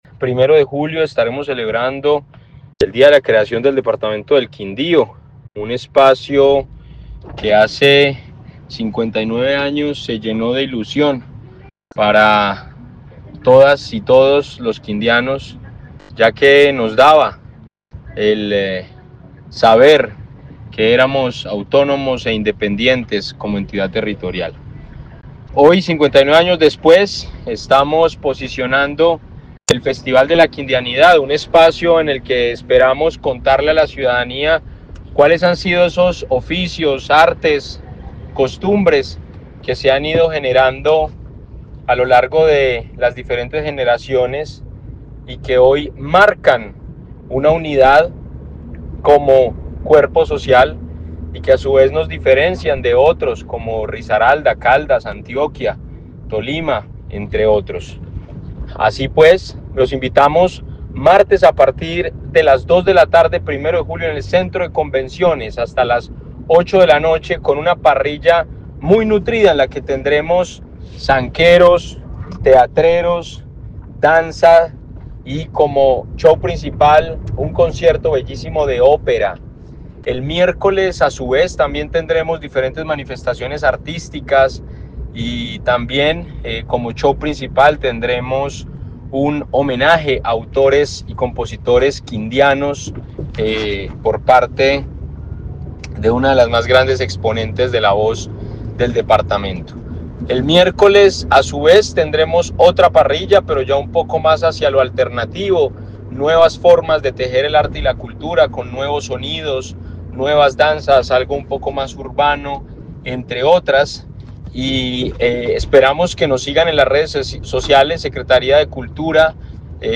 Felipe Robledo, secretario de cultura del Quindío